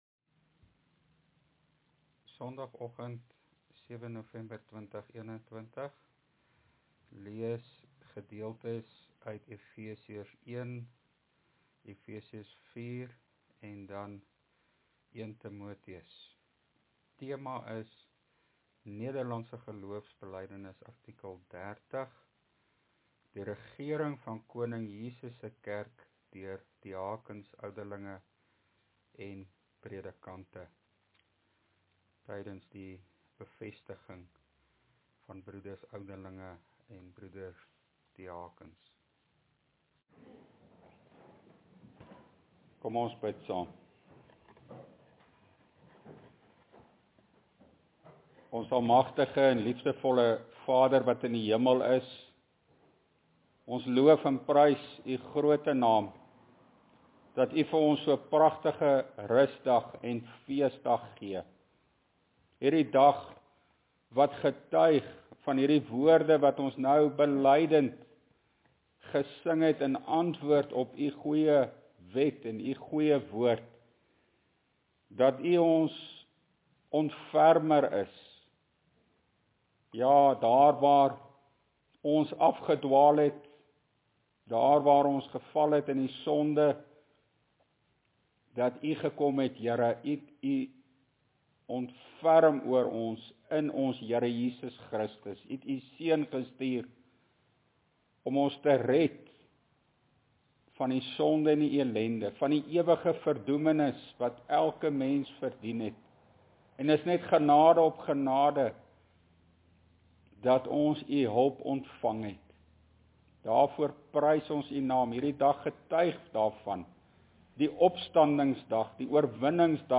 LEER PREDIKING: NGB artikel 30